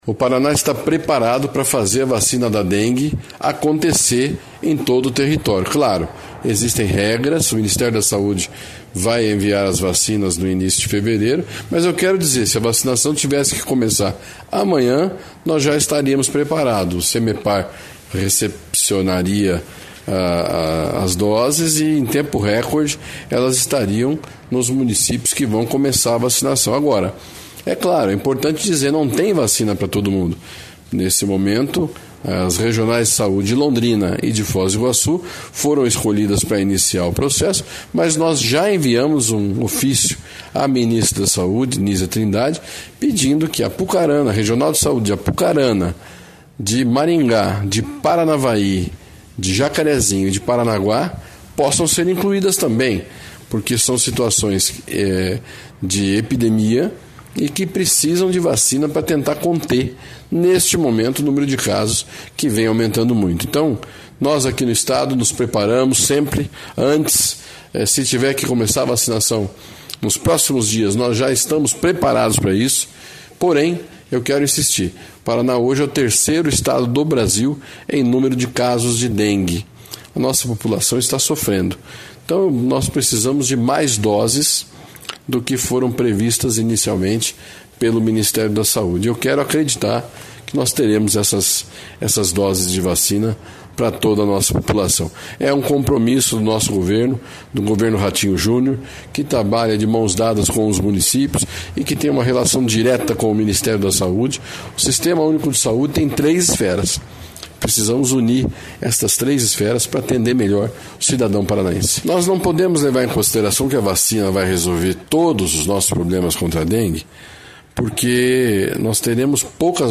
Sonora do secretário da Saúde, Beto Preto, sobre o ofício enviado ao Ministério da Saúde solicitando mais vacinas contra a dengue